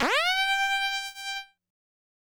synth note05.wav